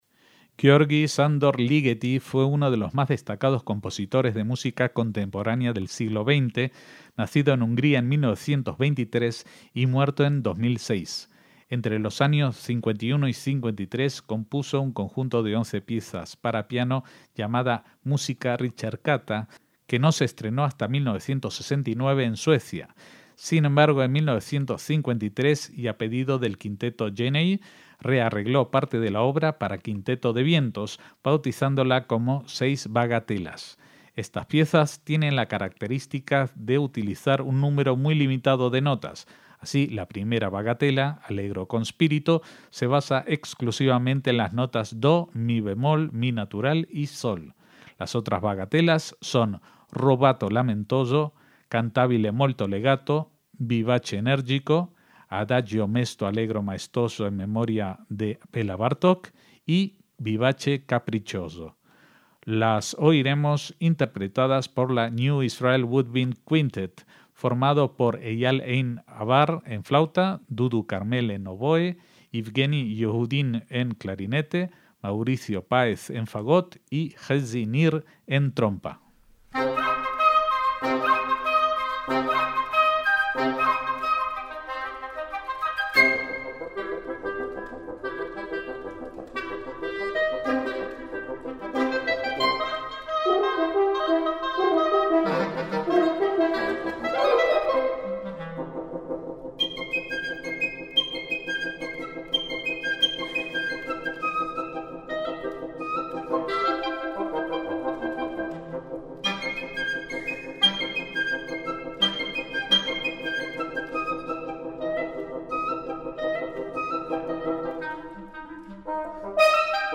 MÚSICA CLÁSICA
para quinteto de viento
flauta
oboe
clarinete
trompa
fagot